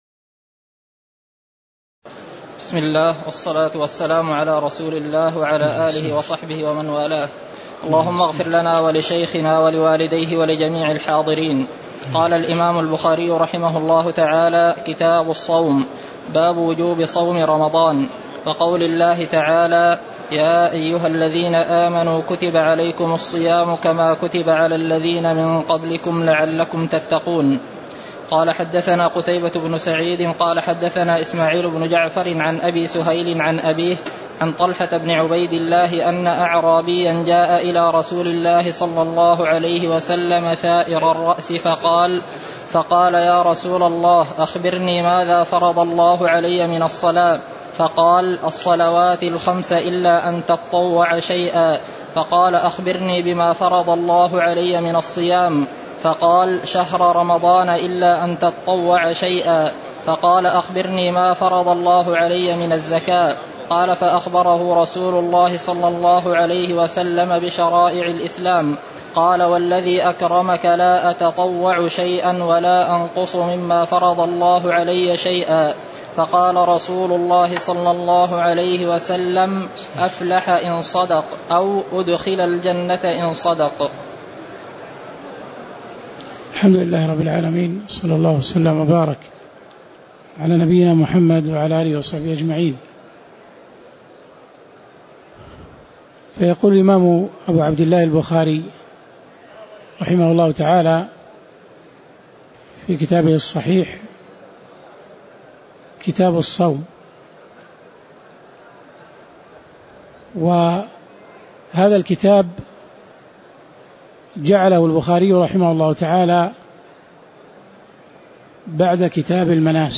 تاريخ النشر ٢ رمضان ١٤٣٨ هـ المكان: المسجد النبوي الشيخ